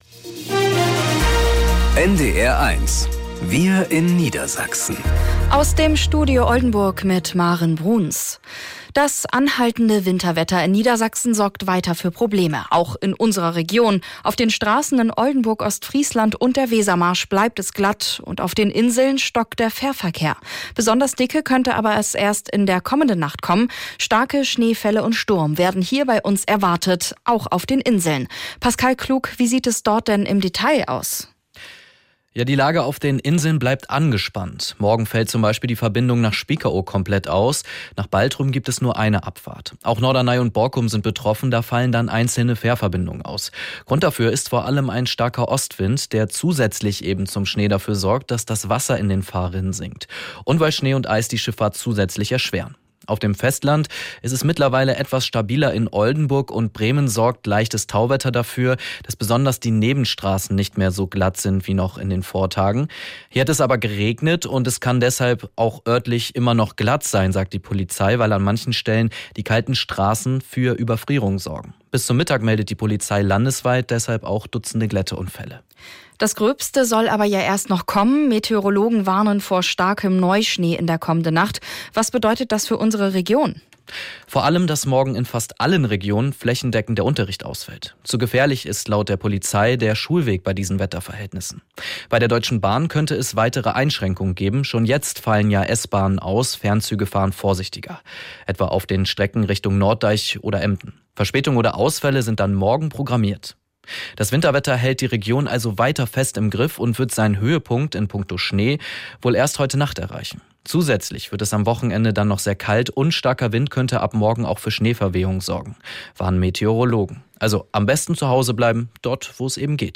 Schnee verursacht Probleme beim Fährverkehr zu den Inseln ~ Wir in Niedersachsen - aus dem Studio Oldenburg | Nachrichten Podcast